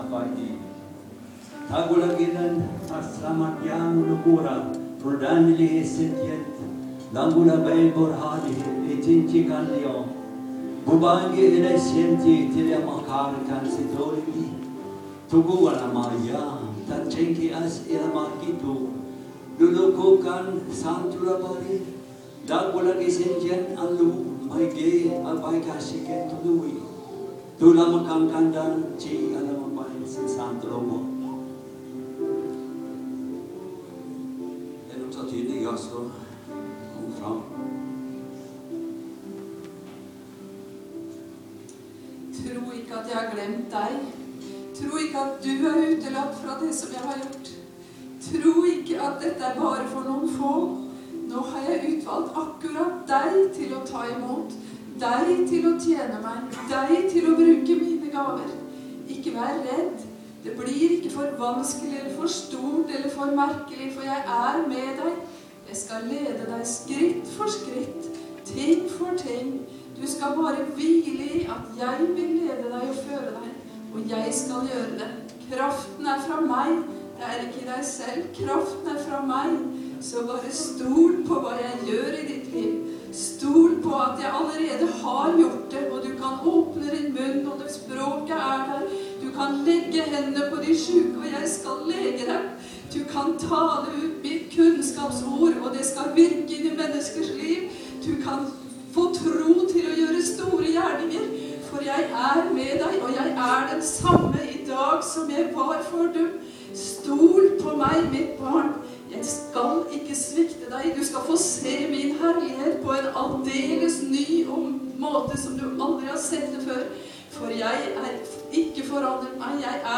TYDING AV TUNGEALE PÅ FORMIDDAGSMØTET: